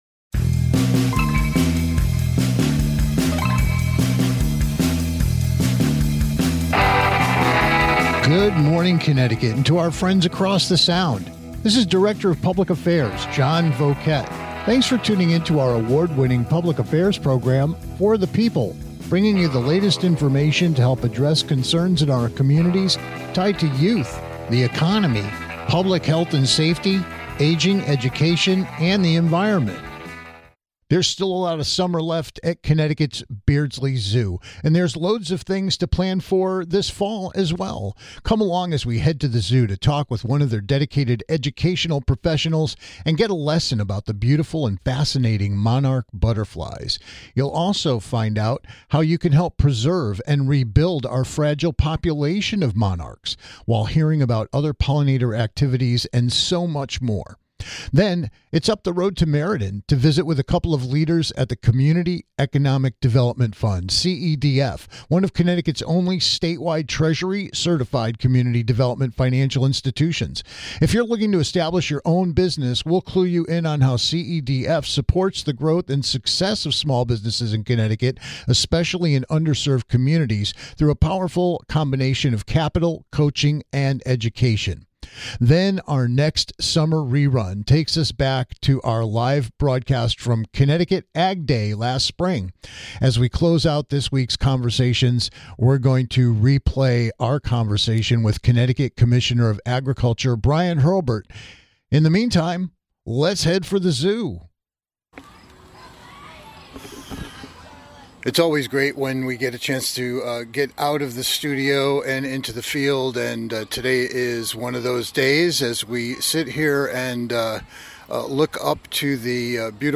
Then our next summer re-run takes us back to our live broadcast from Connecticut Ag Day last spring. As we close out this week's conversations, we'll hear from CT Commissioner of Agriculture Bryan Hurlburt.